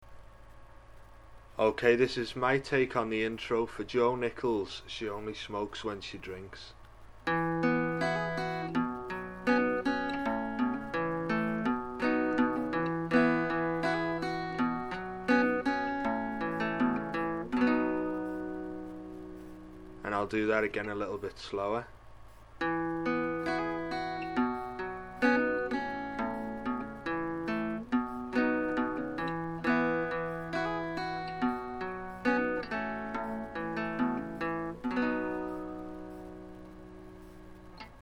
(Intro)
You soundbyte sound beautiful.